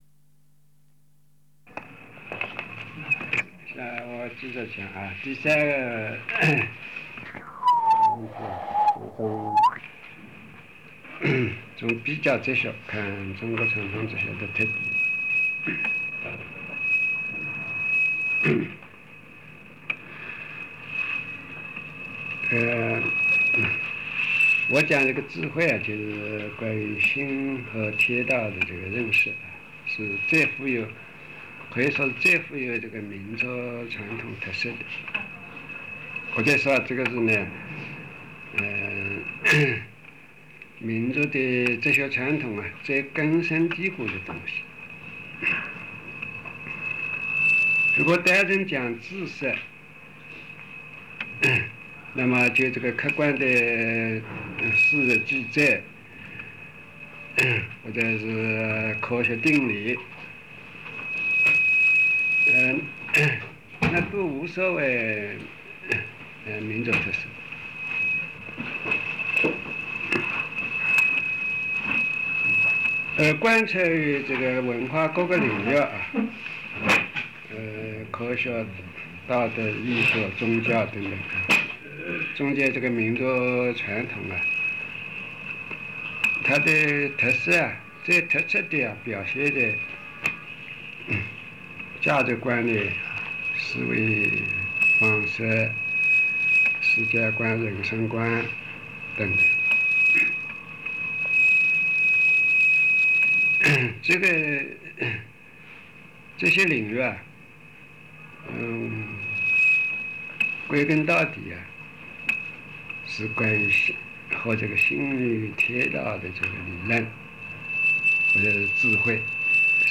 冯契授课录音 智慧说导论第三盘ab面